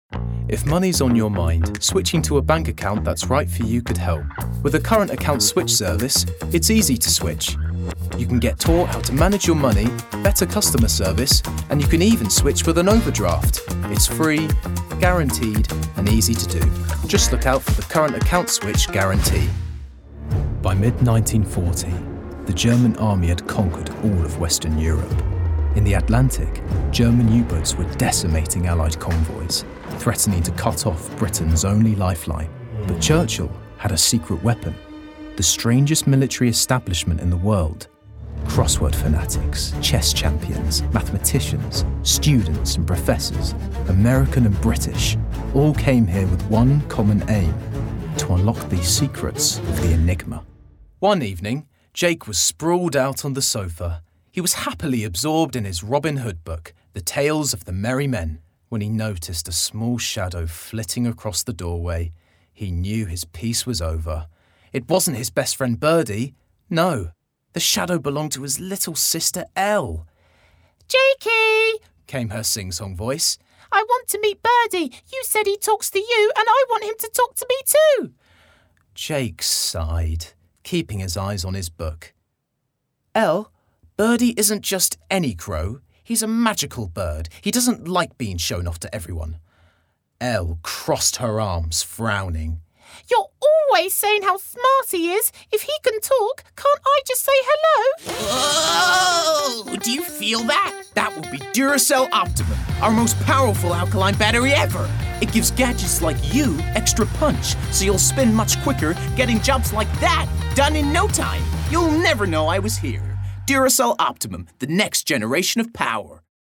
Native voice:
Contemporary RP
Voicereel:
BRITISH ISLES: Heightened RP, Yorkshire, London, MLE
GLOBAL: Standard-American, American-Southern States